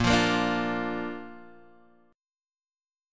Bb chord